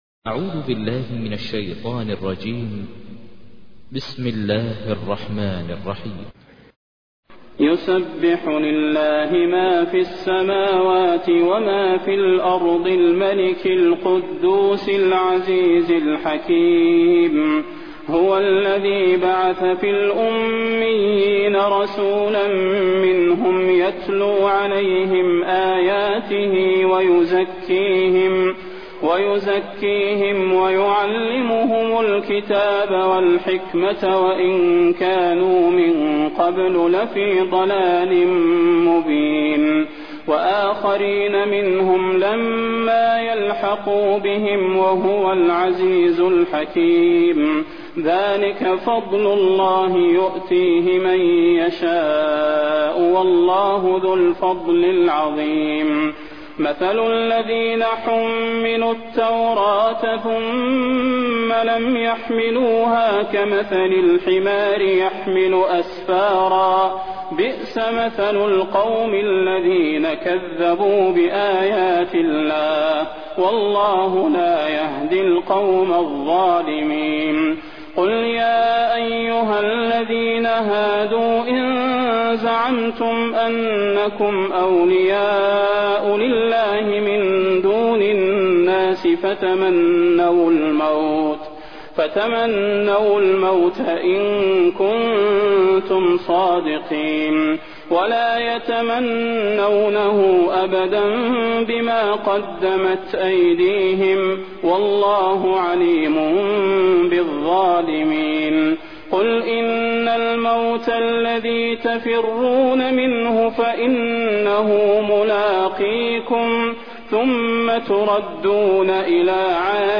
تحميل : 62. سورة الجمعة / القارئ ماهر المعيقلي / القرآن الكريم / موقع يا حسين